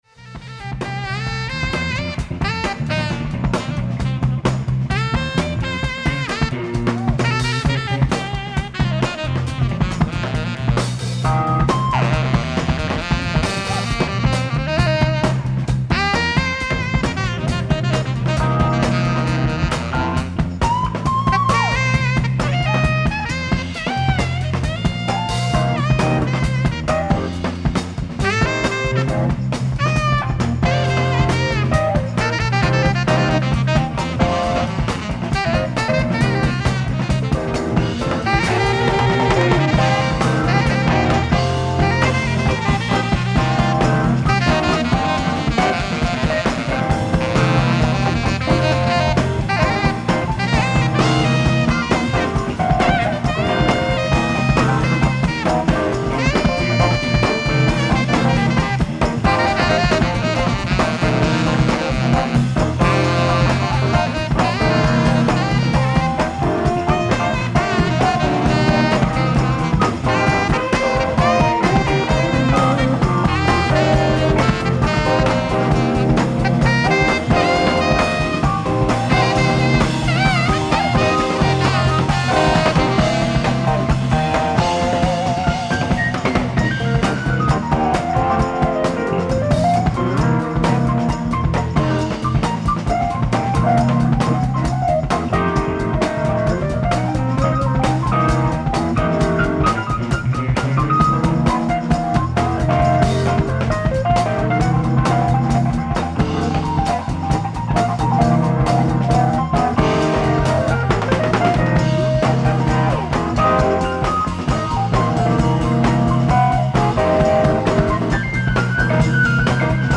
ライブ・アット・エイブリー・フィシャー・ホール、ニューヨーク 07/04/1974
※試聴用に実際より音質を落としています。